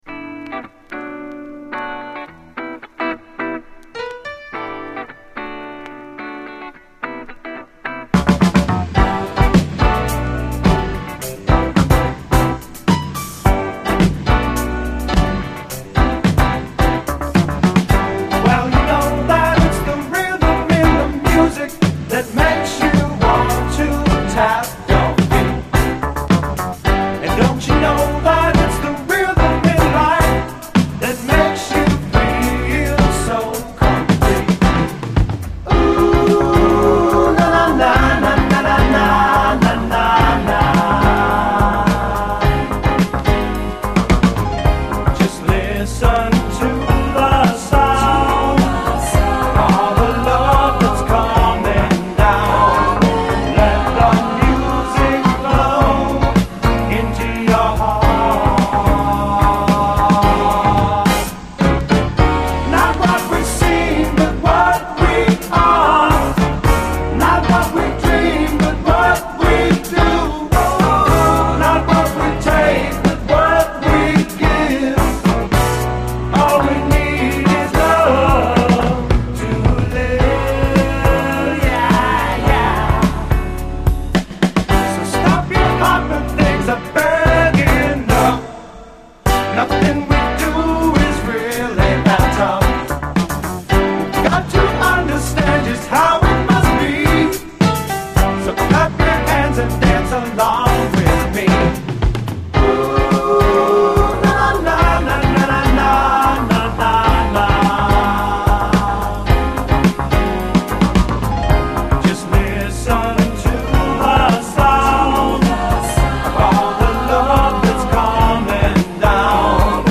SOUL, 70's～ SOUL, SSW / AOR, ROCK
サンディエゴ・ローカルのブルーアイド・ソウル〜AOR自主盤！
ハワイアンAORを思わせる軽やかなコーラスと演奏、そしてメロウネス。
リラックスしたミディアムAOR
アダルト＆ビターなメロウ・グルーヴ
しっとりしたメロウAOR